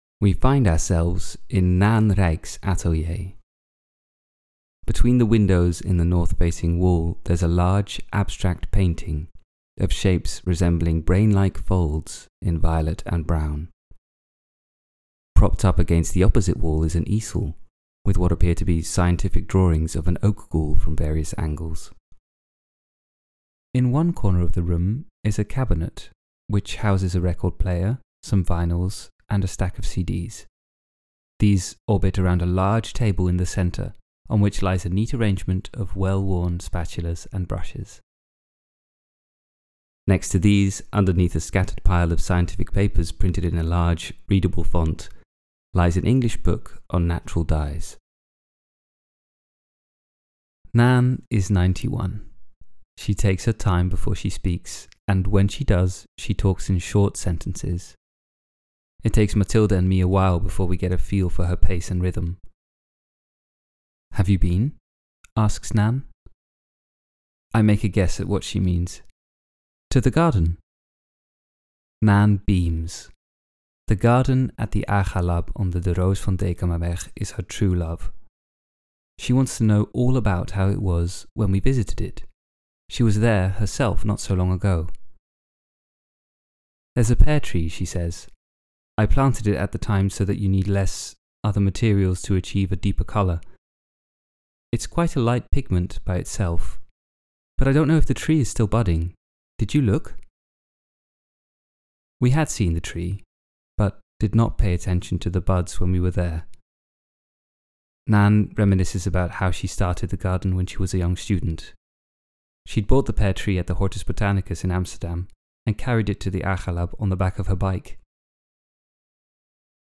She takes her time before she speaks, and when she does, she talks in short sentences.